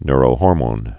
(nrō-hôrmōn, nyr-)